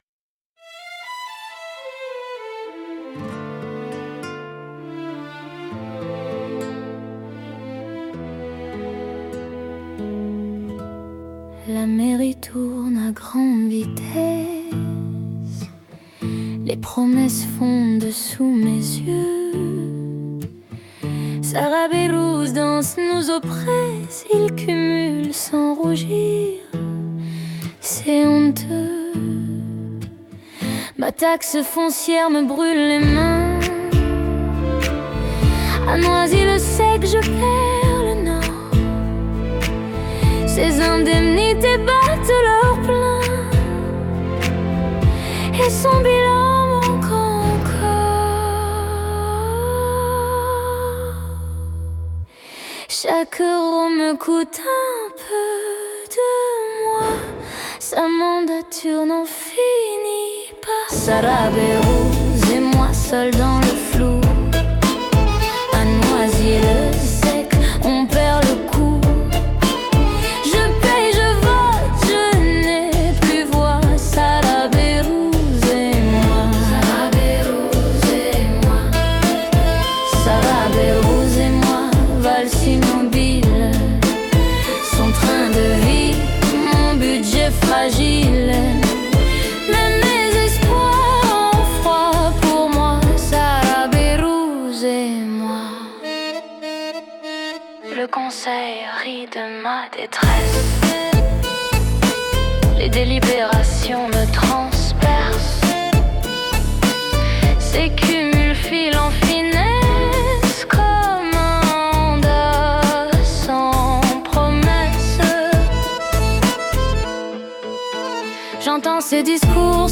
Extrait du conseil municipal